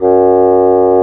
Index of /90_sSampleCDs/Roland L-CDX-03 Disk 1/WND_Bassoons/WND_Bassoon 4
WND BSSN F#2.wav